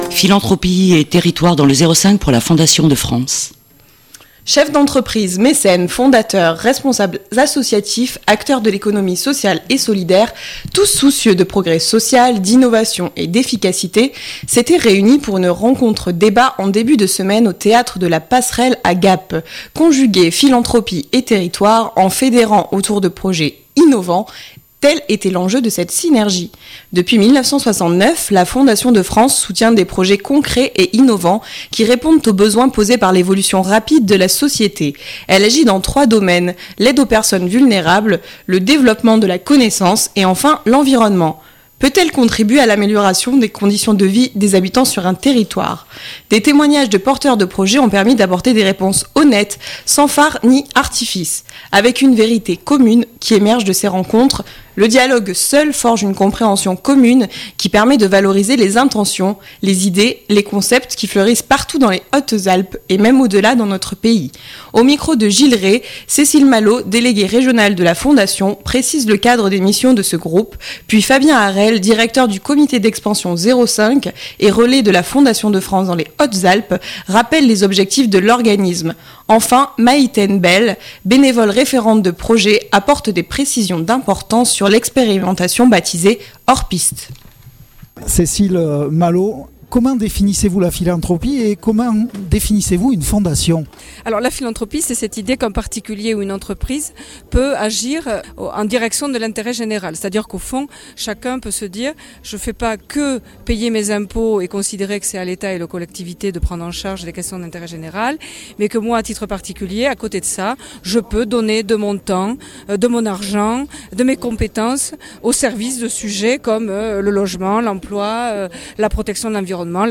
Chefs d’entreprises, mécènes, fondateurs, responsables associatifs, acteurs de l’Economie Sociale et Solidaire (ESS), tous soucieux de progrès social, d’innovation et d’efficacité s’étaient réunis pour une rencontre-débat mardi 24 mars au théâtre de la passerelle à Gap. Conjuguer Philanthropie et territoire en fédérant autour de projets innovants, tel était l’enjeu de cette synergie.